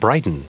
Prononciation du mot brighten en anglais (fichier audio)
Prononciation du mot : brighten